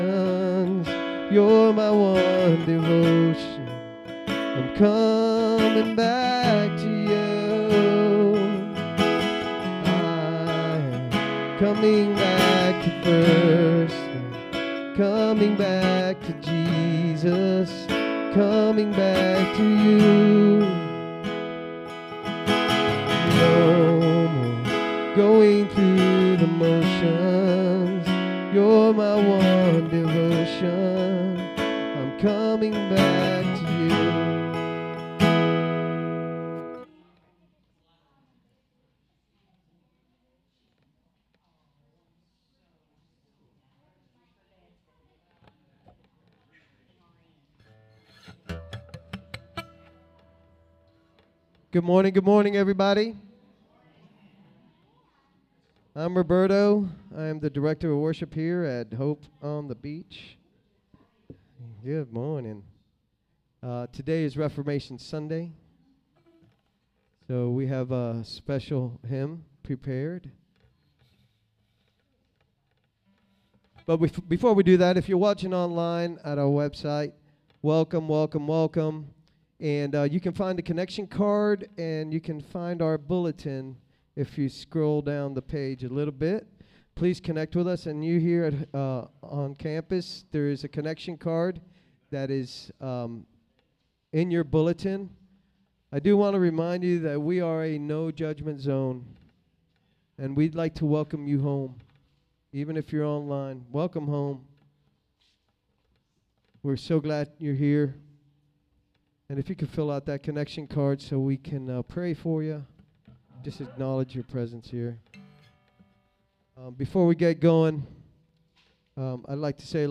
SERMON DESCRIPTION When Jacob ran into the wilderness, alone and afraid, God met him with mercy and a promise.